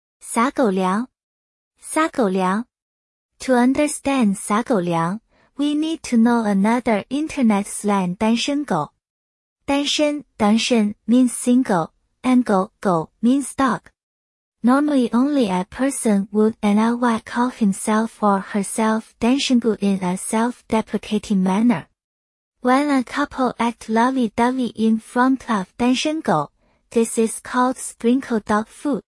sā gǒu liáng